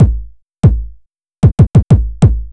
Track 13 - Kick Beat 02.wav